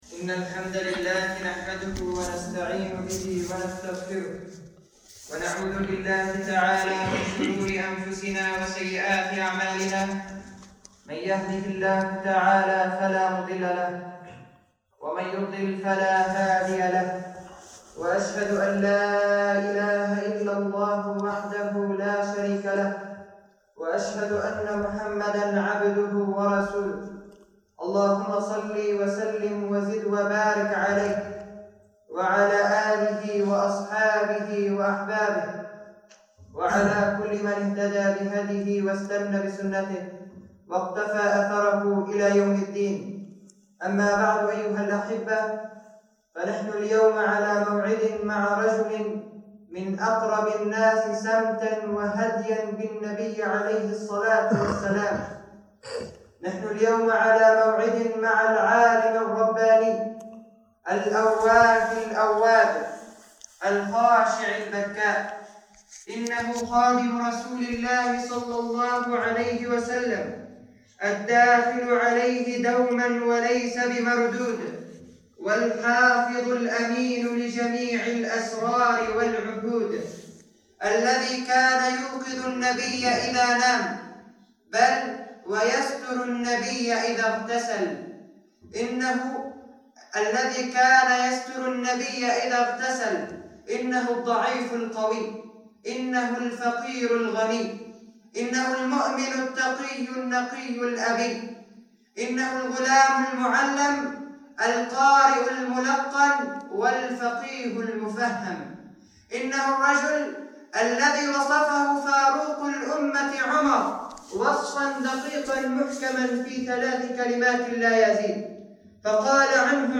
[خطبة جمعة] عبدالله بن مسعود رضي الله عنه
المكان: مسجد إيزال-الضنية